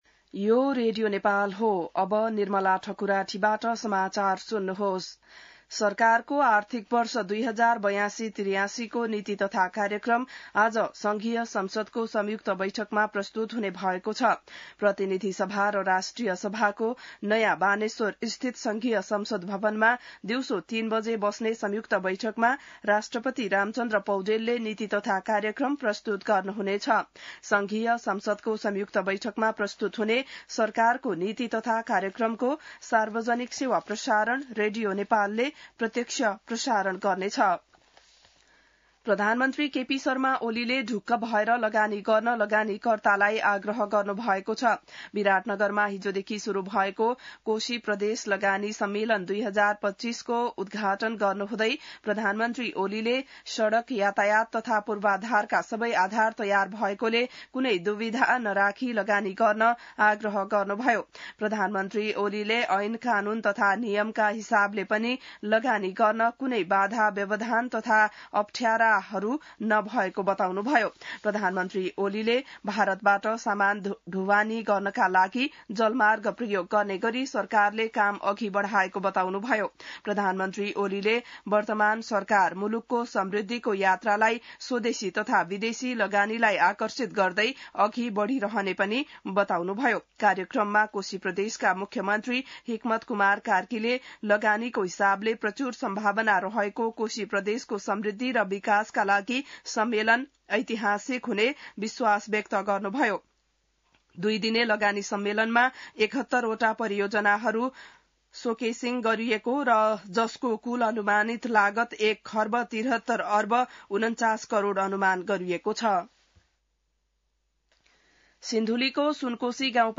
बिहान ६ बजेको नेपाली समाचार : १९ वैशाख , २०८२